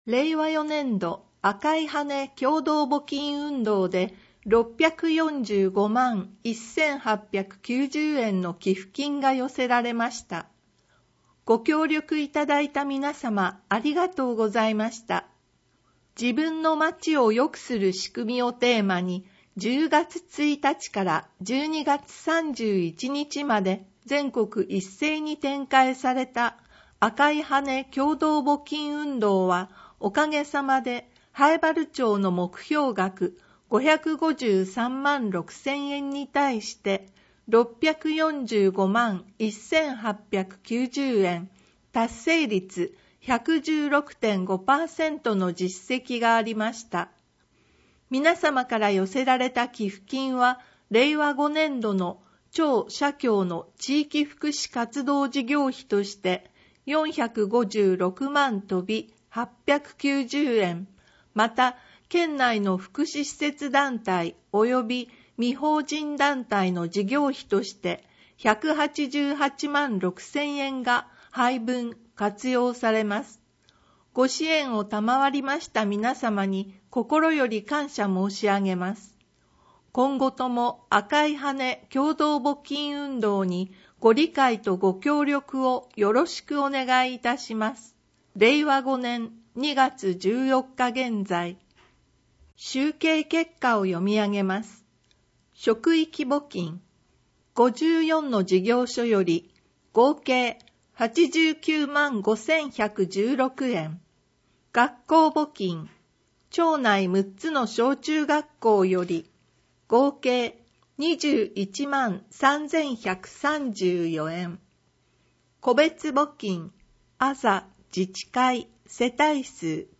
音訳ファイル